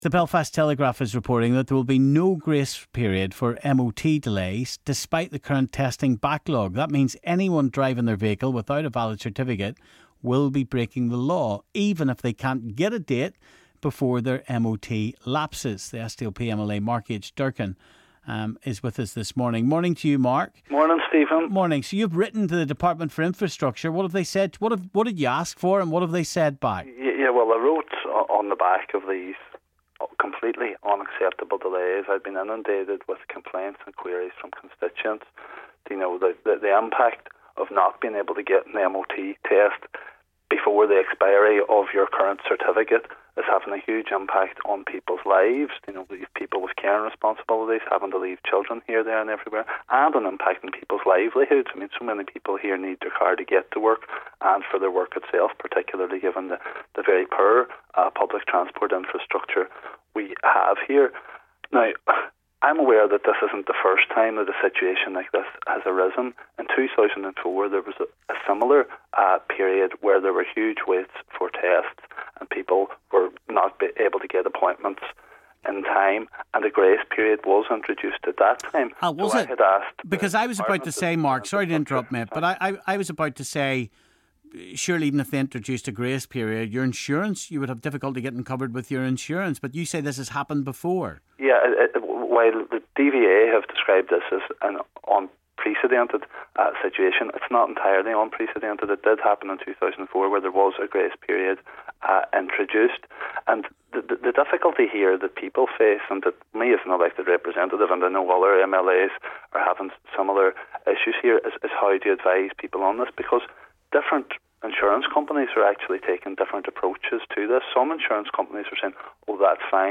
SDLP MLA Mark H Durkan had written to the Department for Infrastructure to request the the grace period - he spoke to Stephen this morning.